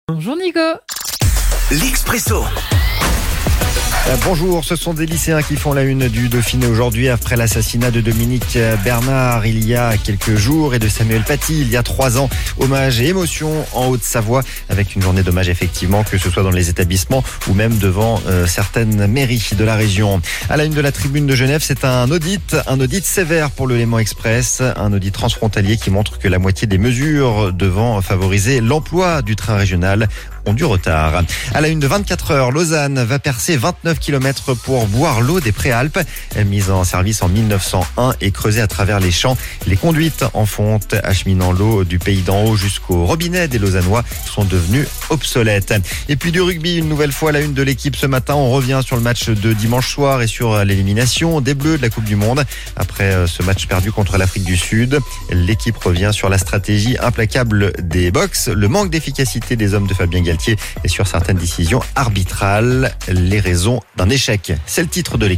La revue de presse